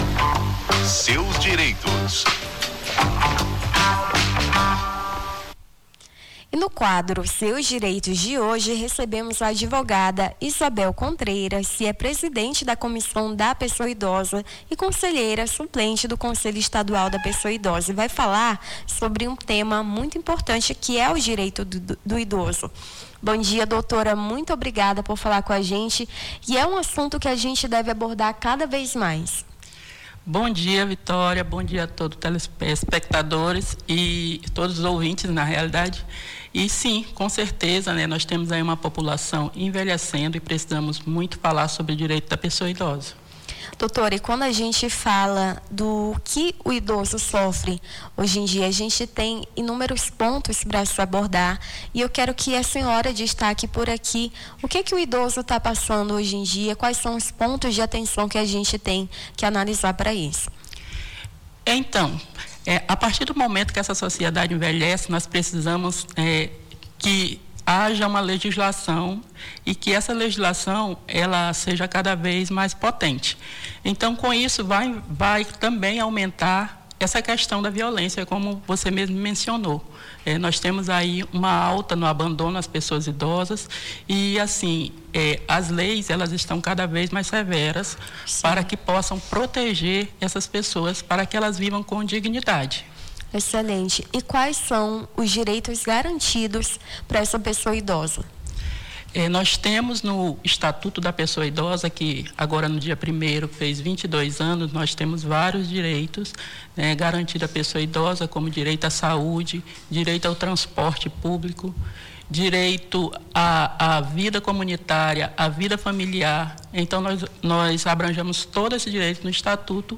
O Jornal da Manhã conversou sobre os direitos dos idosos, com a advogada